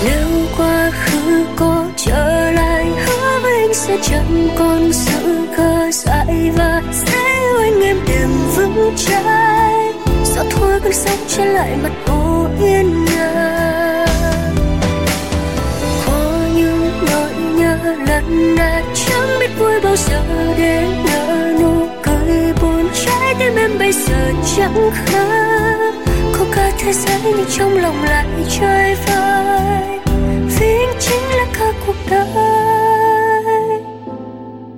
Nhạc Trẻ.